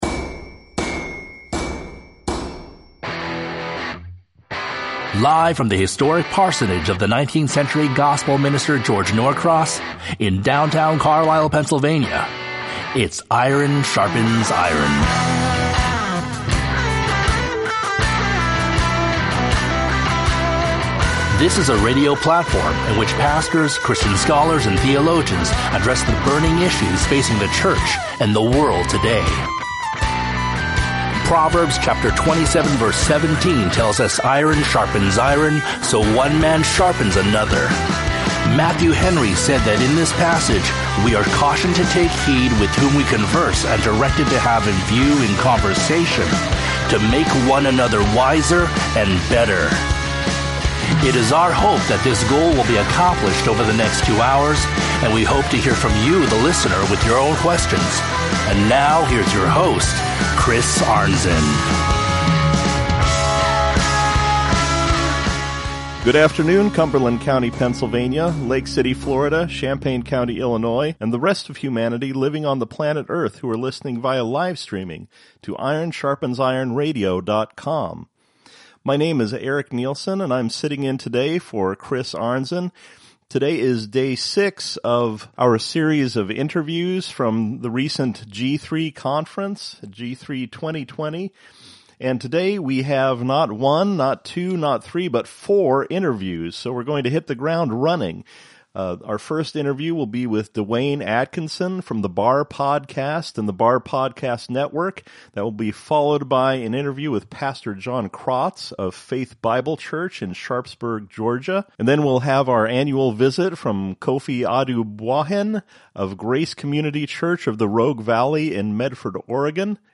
Featuring Interviews from the 2020 G3 Conference with